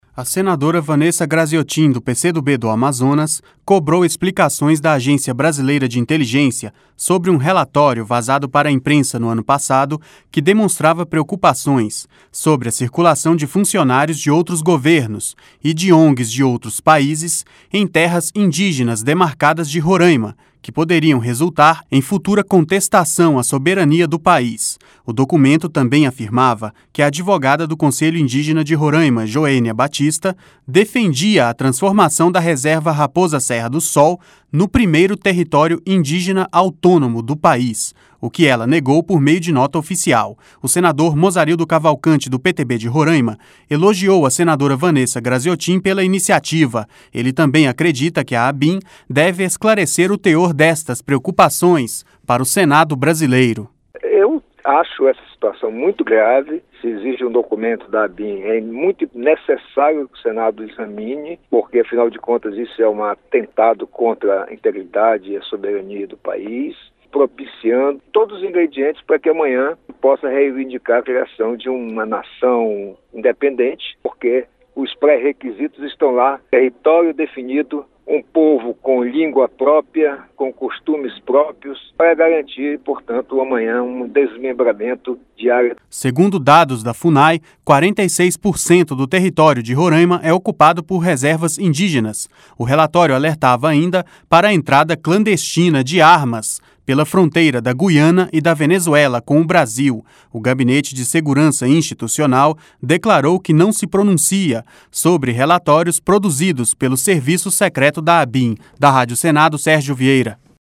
O senador Mozarildo Cavalcanti, do PTB de Roraima, elogiou a senadora Vanessa Graziottin pela iniciativa.